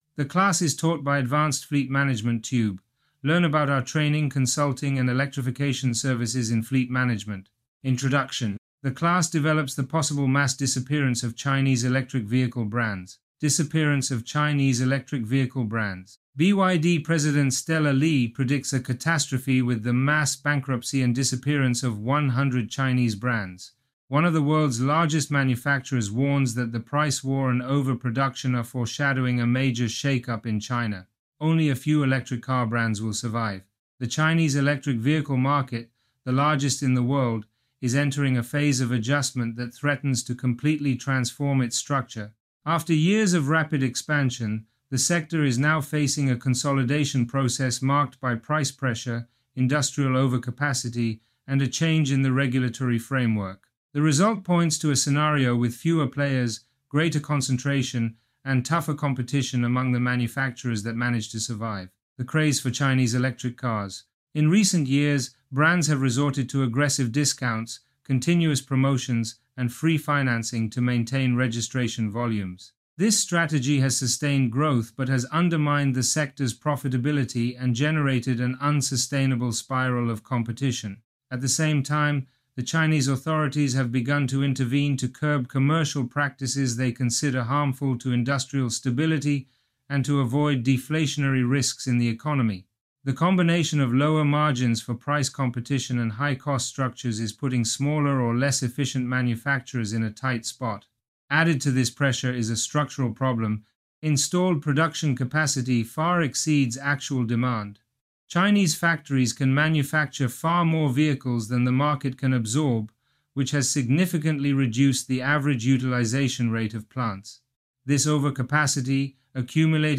Online class